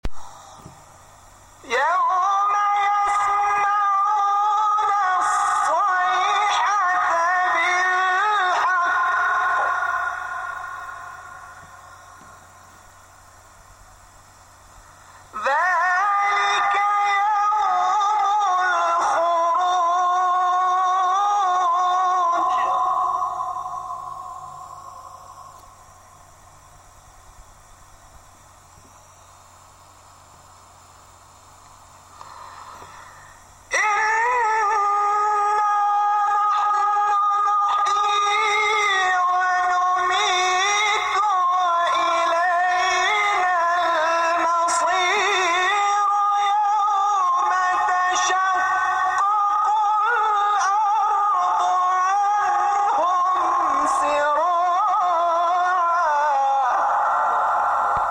شبکه اجتماعی: مقاطع صوتی از قاریان ممتاز کشور را می‌شنوید.